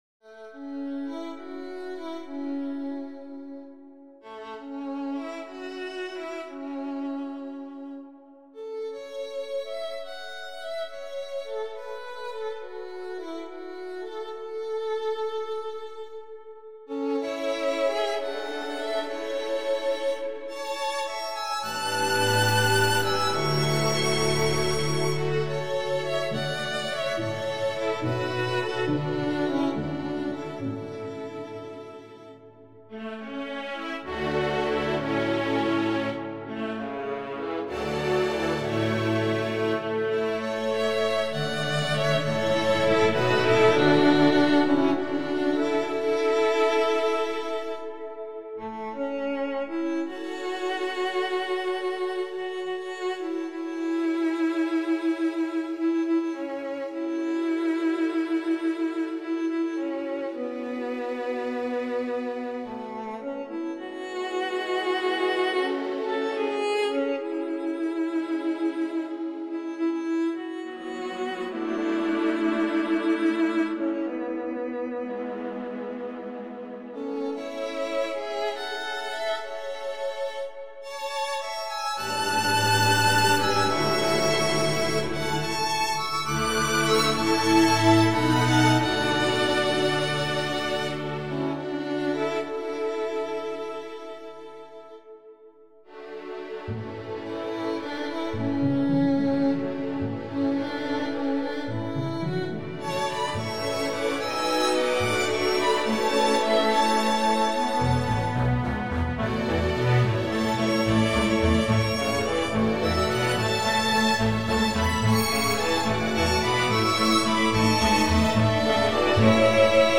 Voicing: 2 Solo Violin w/ SO acc